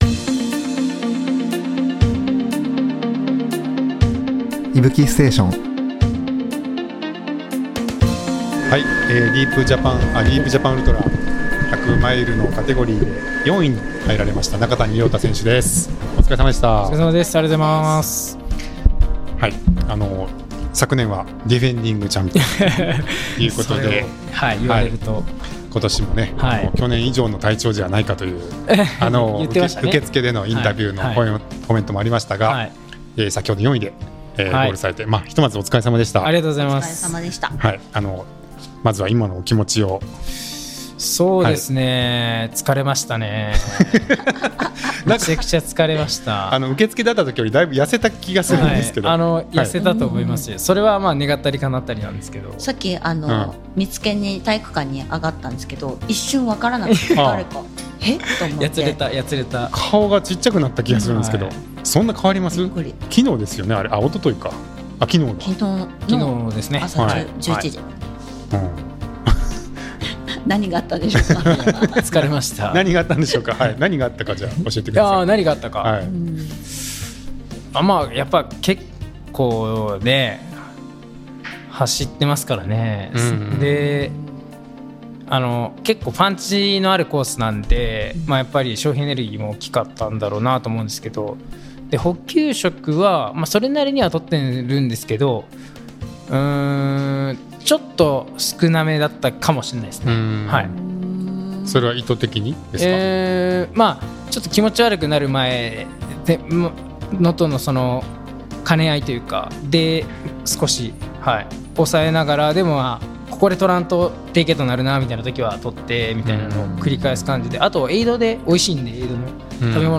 Deep Japan Ultra 100公式Liveから、選り抜きの内容をポッドキャストでお届けします！